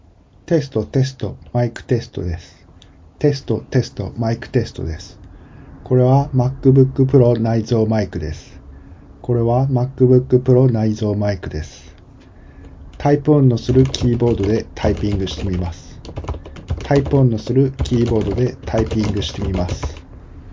M1 MacBook Pro 2021 内蔵マイク
録音音声
マイクを強化したというMacBook Proも良い感じでした。環境の音をかなり拾ってしまいます。外の自動車が走る音が入っています。キーボードのタイプ音（本体キーボードではありません）も大きく聞こえます。